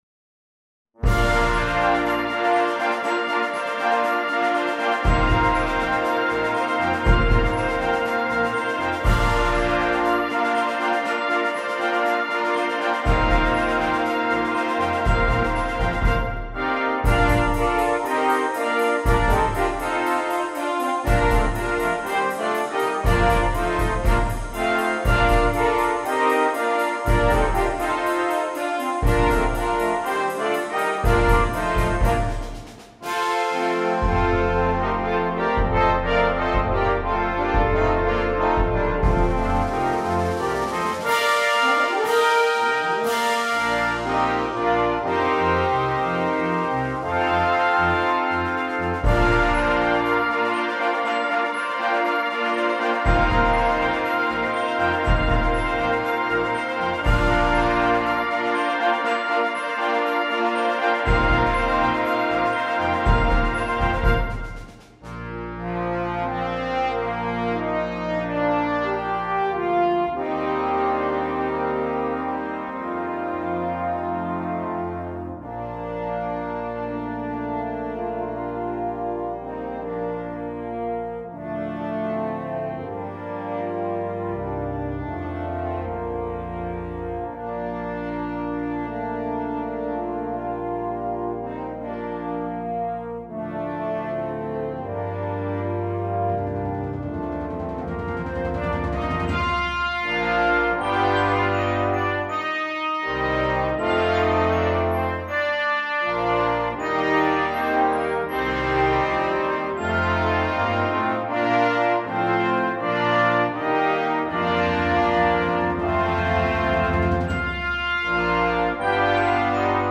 2. Brass Band
Full Band
without solo instrument
Christmas Music